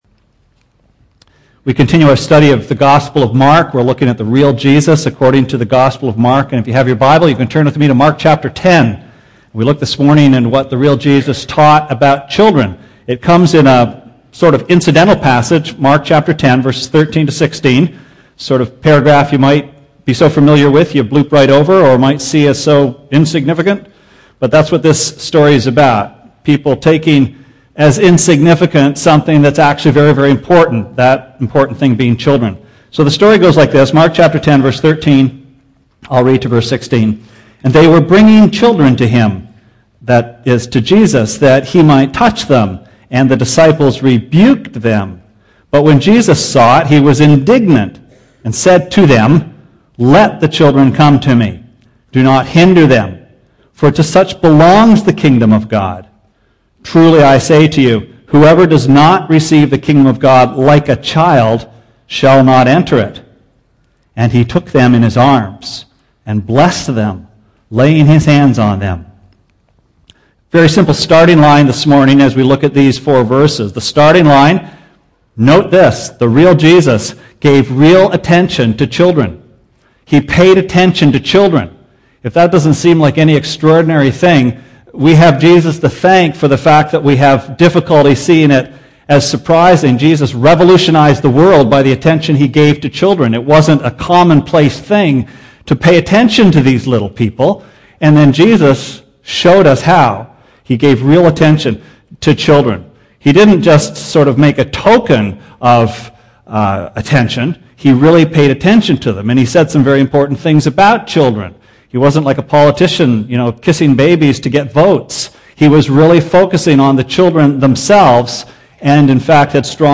Sermon Archives May 10